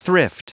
Prononciation du mot thrift en anglais (fichier audio)
Prononciation du mot : thrift